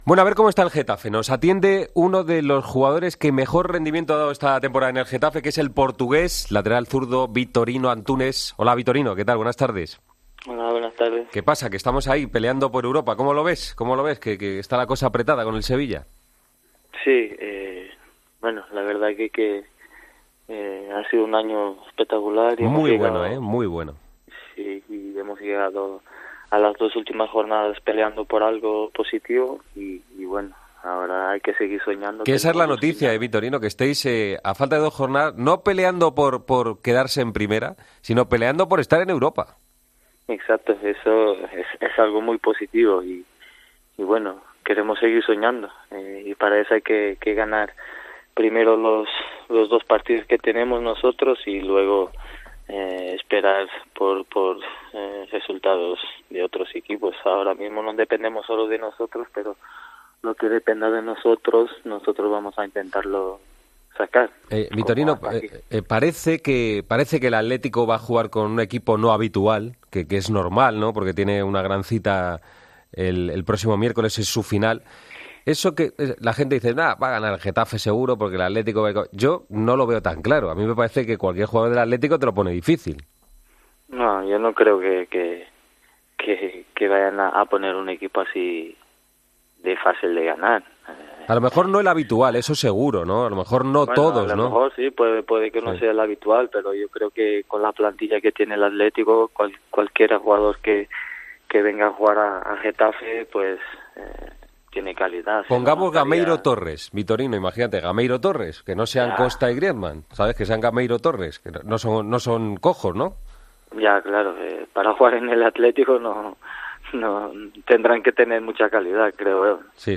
El lateral izquierdo luso habló en Deportes COPE del trascendental partido ante el Atlético de Madrid de este sábado y calificó de sueño el "llegar a las últimas jornadas peleando por la Europa League". Sobre su futuro apuntó que quiere seguir en el Getafe pero "es el club el que debe ejercer la opción de compra".